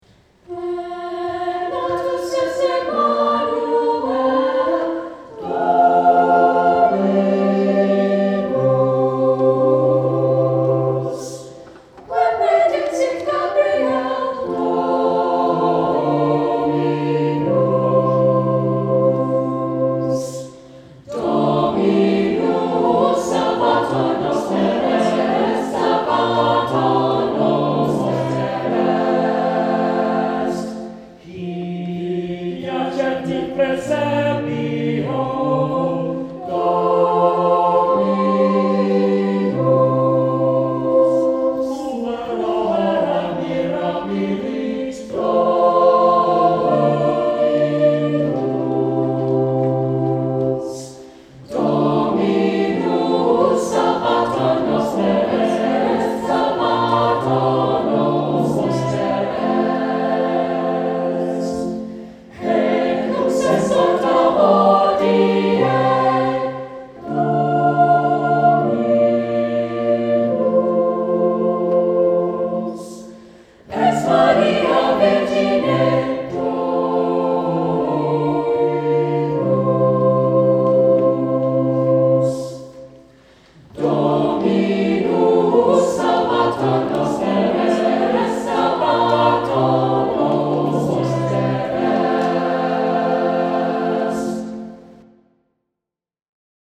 Saint Clement Choir Sang this Song
Anthem , Christmas-Vigil